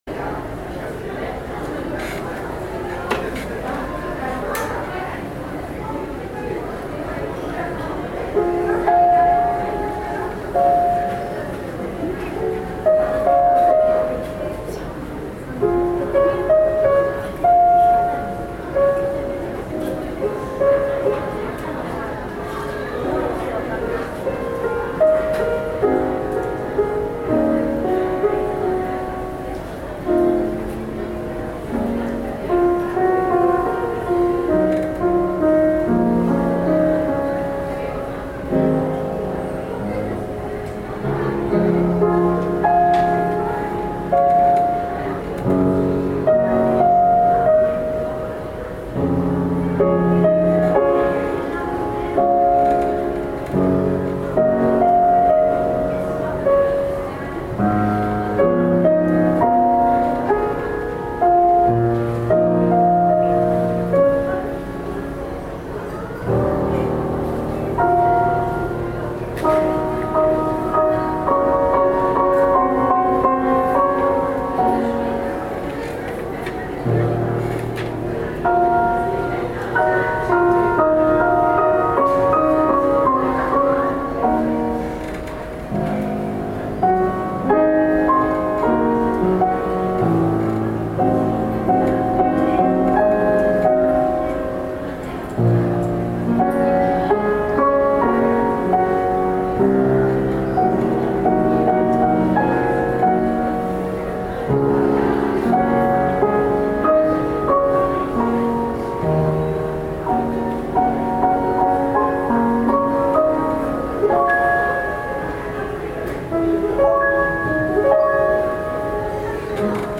２０１４年９月１４日（日）　定禅寺ストリートジャズフェスティバルin仙台（電力ビルグリーンプラザ）
ピアノソロ
今回はグランドピアノの音をマイクで拾ってスピーカーから出しているみたいだけど、それをなんとか感じ取ることができた。
昨年とは違い、高速化しない月の光になったと思う。
jazz-fes-2014-tsuki.mp3